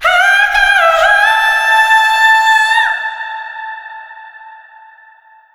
SCREAM12  -R.wav